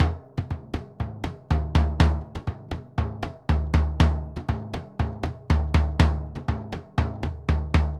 Bombo_Merengue 120_1.wav